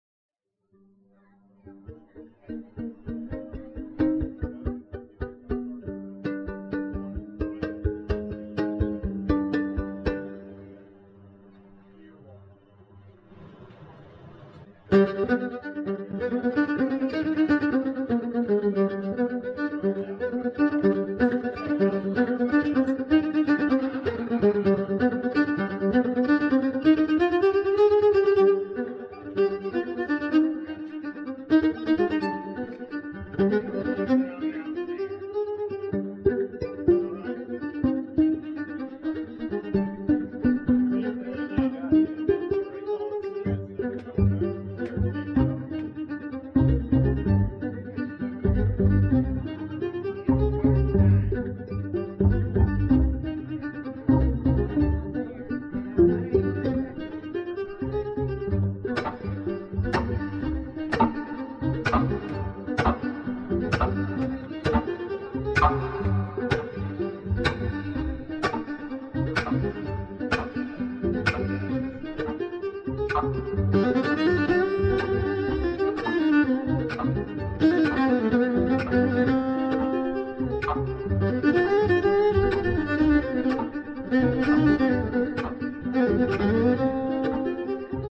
Balad / Old Western